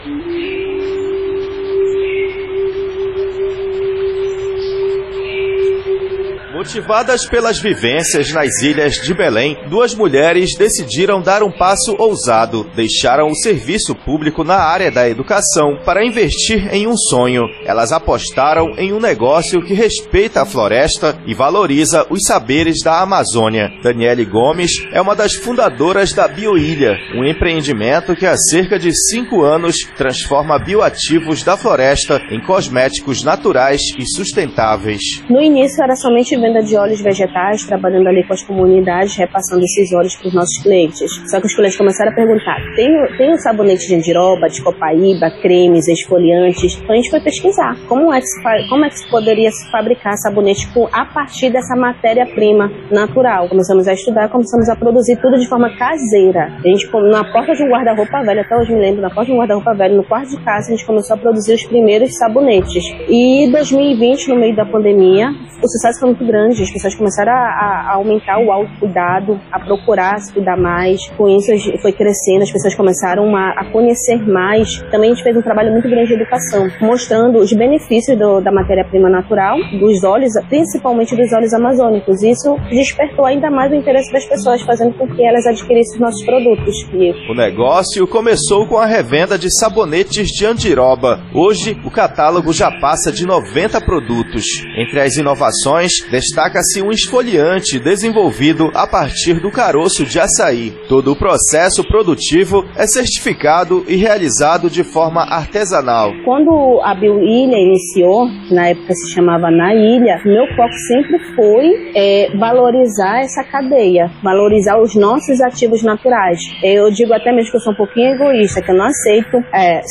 A reportagem especial da CBN AMAZÔNIA Belém, apresenta o empreendedorismo feminino que impulsiona a bioeconomia, gera empregos verdes, empoderamento e preserva o meio ambiente.